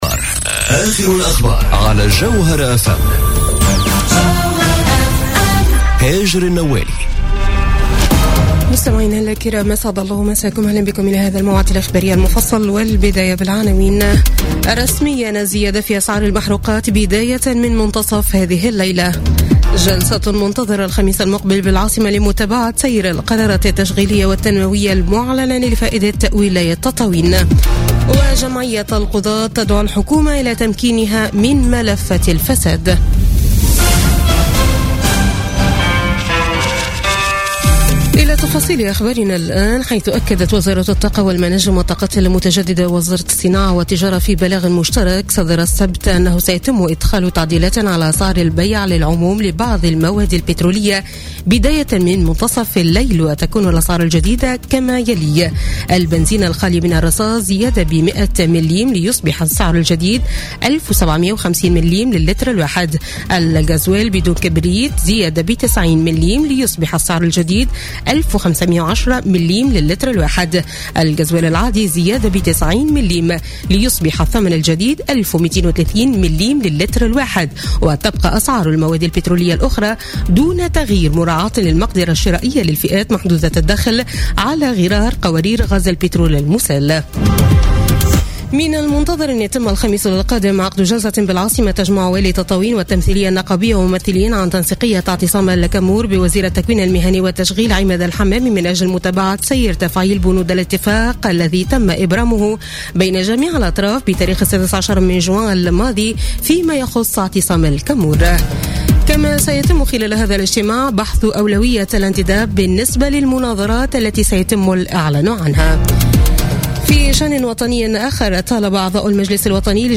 نشرة أخبار منتصف الليل ليوم الأحد 2 جويلية 2017